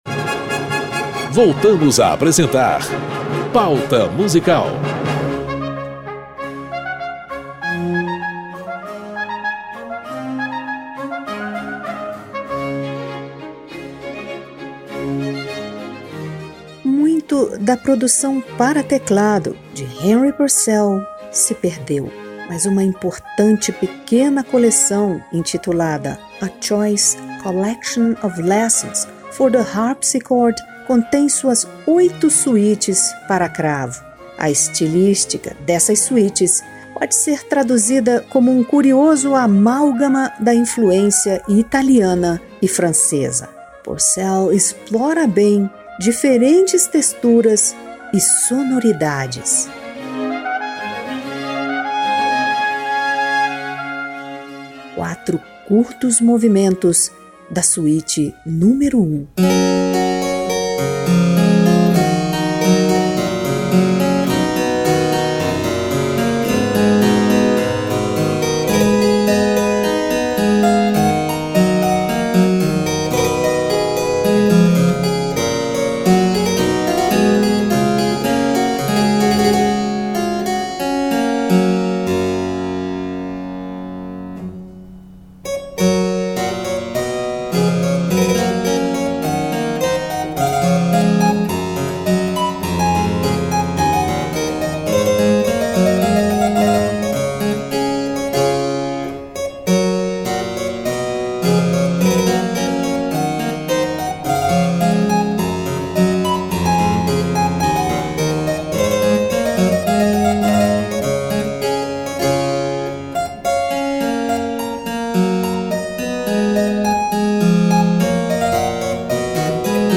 Uma atmosfera sonora da Inglaterra do século XVII com um dos maiores compositores da era barroca e da história da música britânica.
soprano
cravo
suítes, canções, óperas e transcrições para cravo